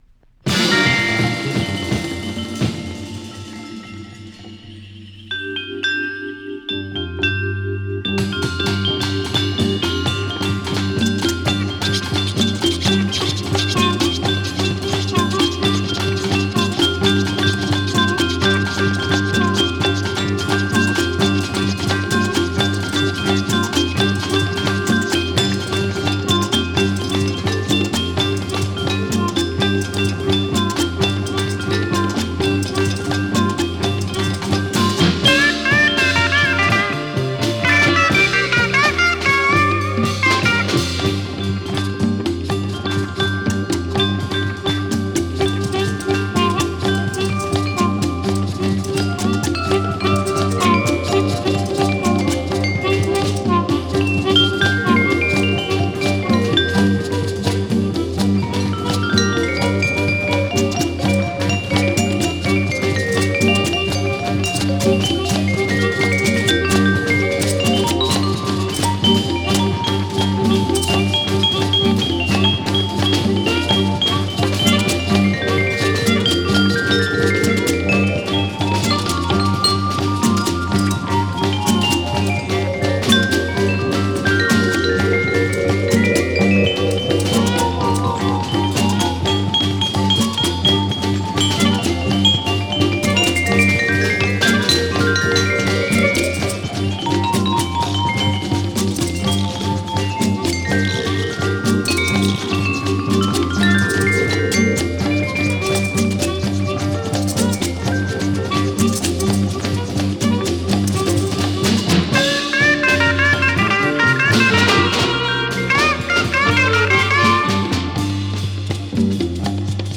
Саксофон, флейта, виброарфа